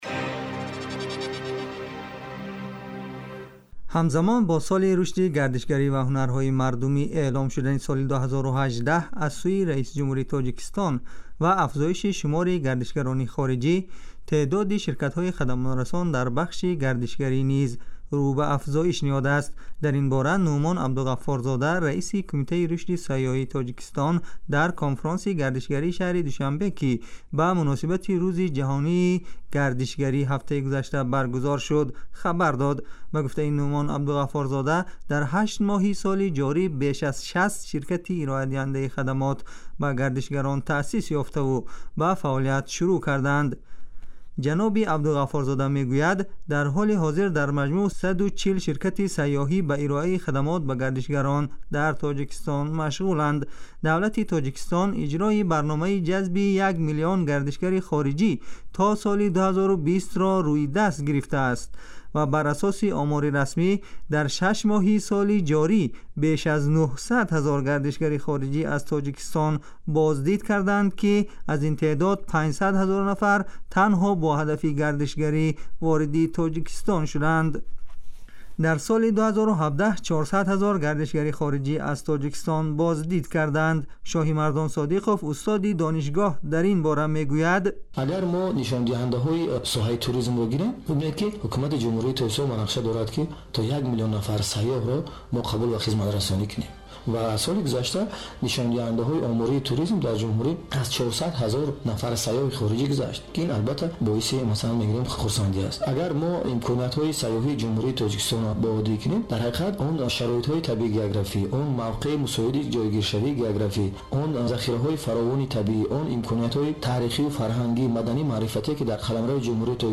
گزارش ویژه : موانع آسیب زا برای توسعه گردشگری در تاجیکستان